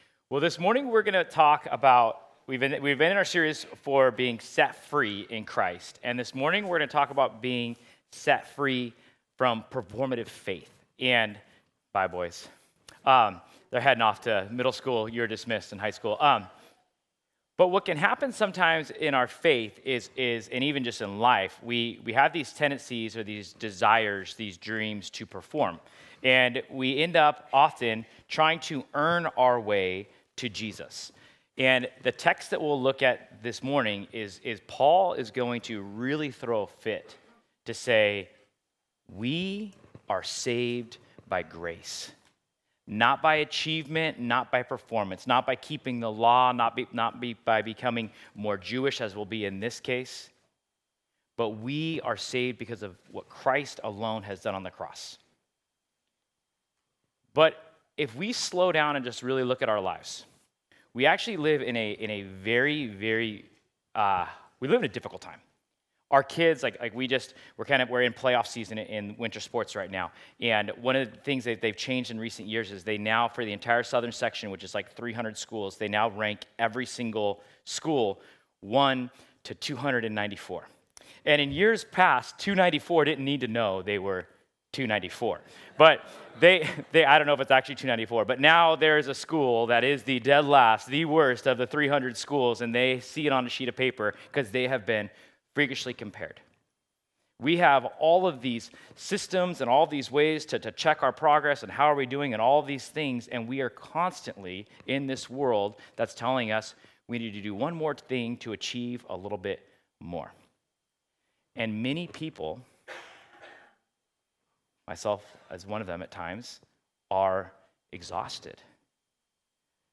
3:22 Service Type: Sunday Trying harder won’t set you free.